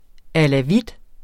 Udtale [ alaˈvid ]